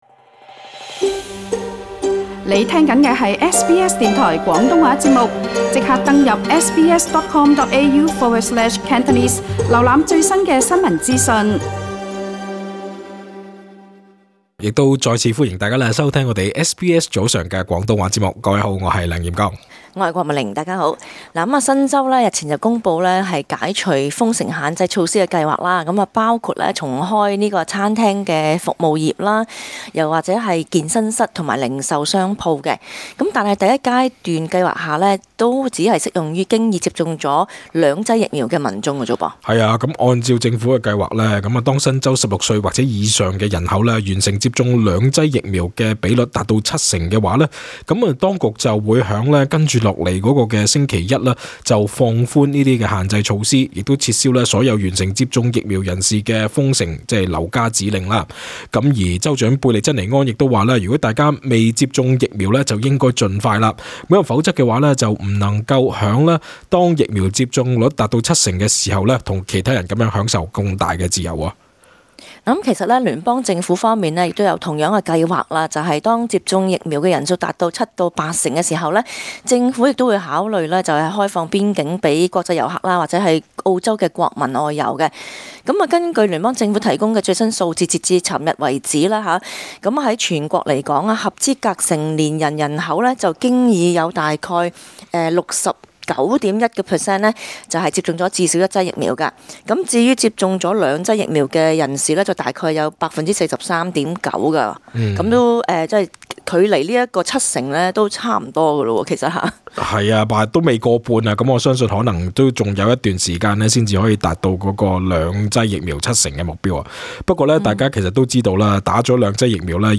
本節目內嘉賓及聽眾意見並不代表本台立場 。